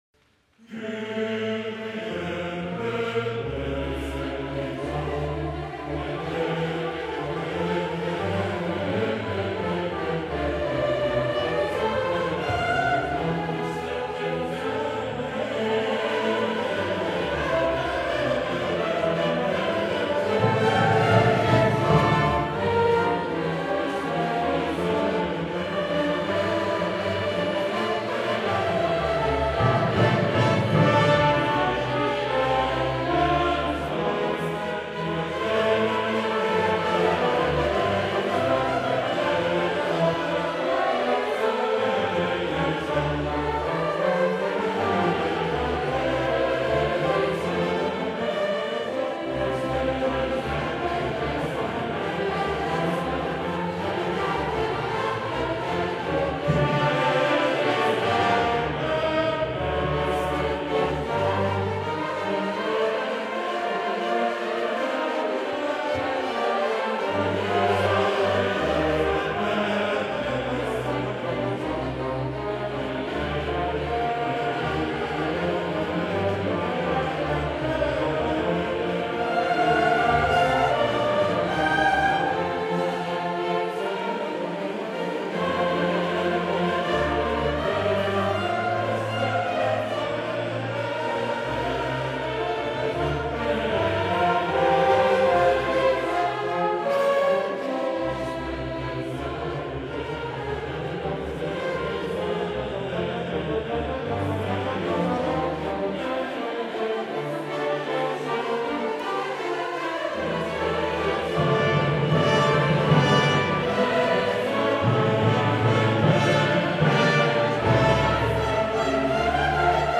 Web del orfeón de Granada